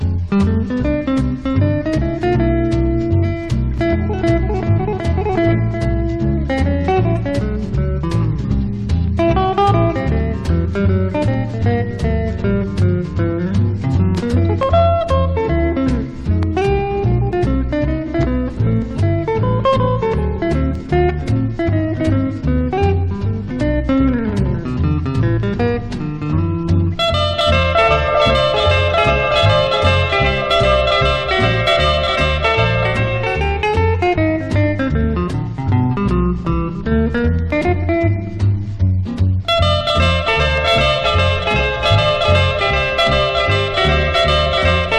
Pop, Jazz, Easy Listening　Germany　12inchレコード　33rpm　Stereo